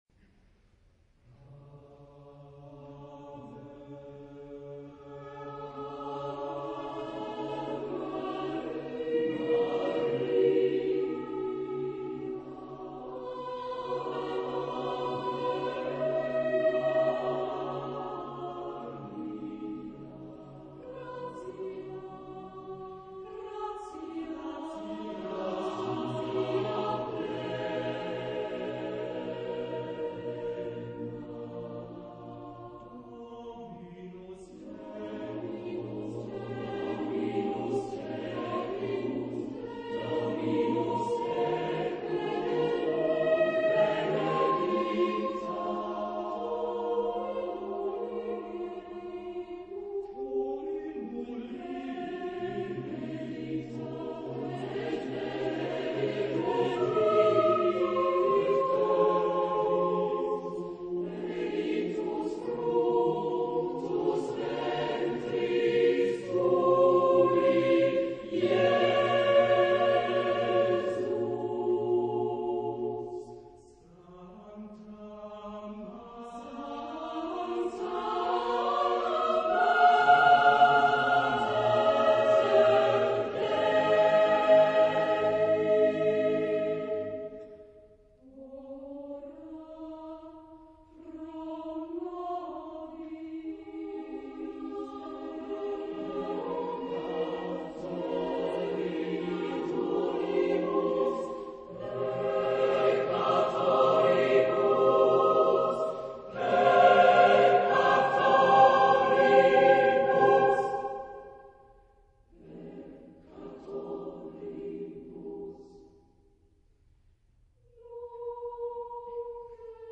Genre-Stil-Form: geistlich ; Motette ; Gebet
Chorgattung: SSAATTBB  (8-stimmiger gemischter Chor )
Tonart(en): frei
von Konzertchor des GoetheGymnasiums Gera gesungen
Aufnahme Bestellnummer: 7. Deutscher Chorwettbewerb 2006 Kiel